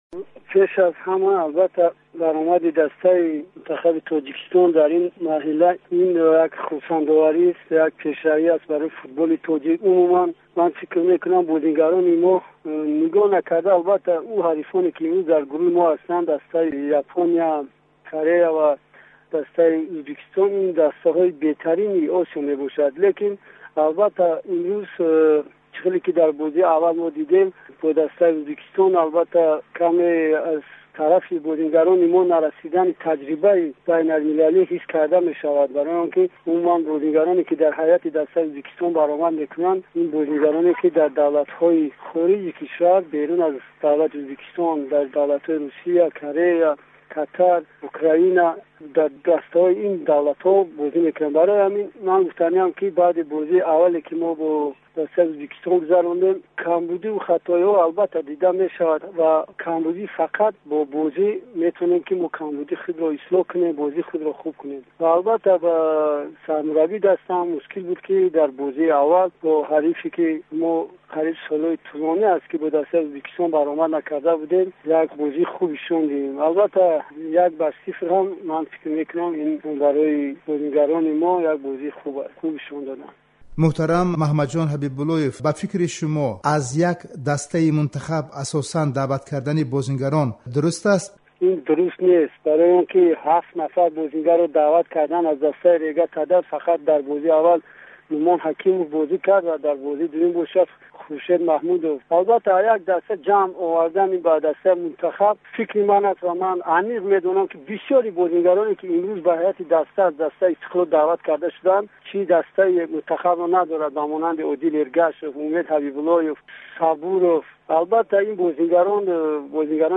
Гуфтугӯи ихтисосӣ